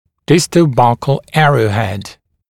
[ˌdɪstə(u)ˈbʌk(ə)l ‘ærəuhed][ˌдисто(у)ˈбак(э)л ‘эроухэд]крепежный проволочный элемент кламмера, охватывающий зуб с дистальной и щечной сторон